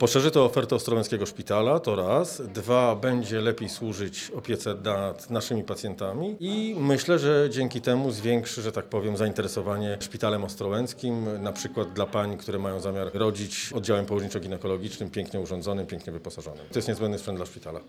Wystąpienie